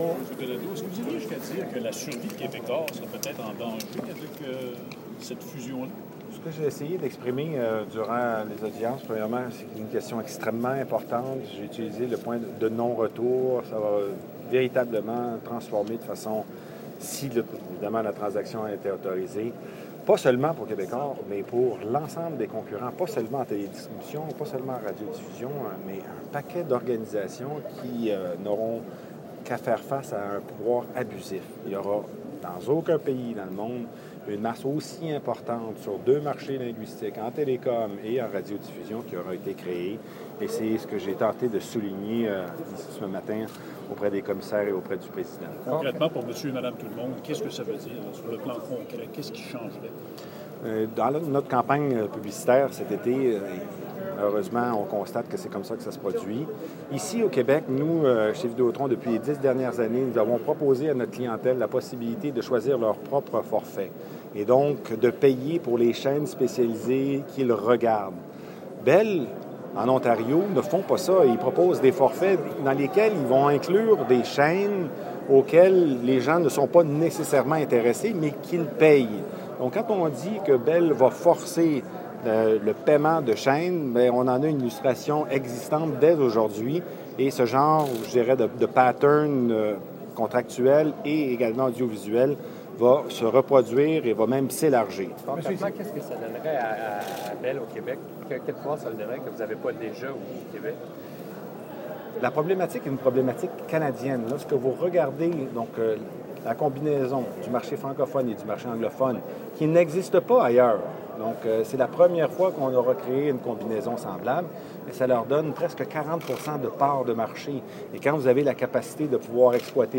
CRTC, September 11 scrume